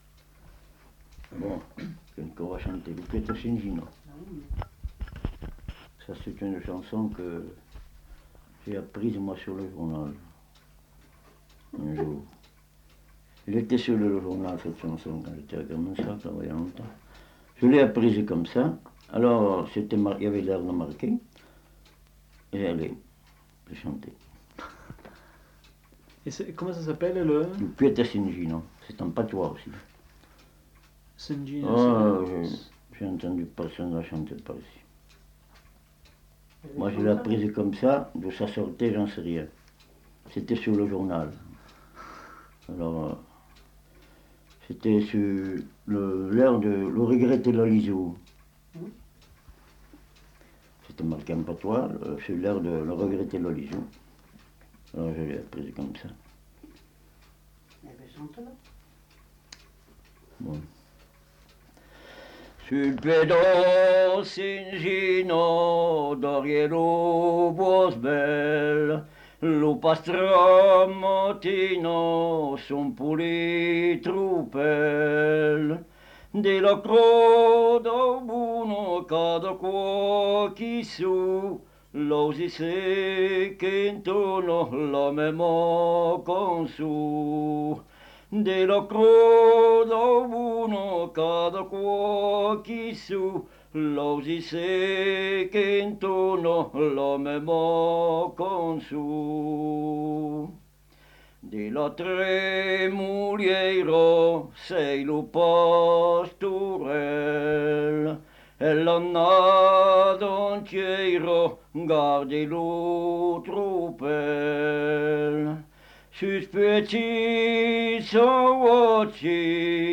Aire culturelle : Viadène
Lieu : Vernholles (lieu-dit)
Genre : chant
Effectif : 1
Type de voix : voix d'homme
Production du son : chanté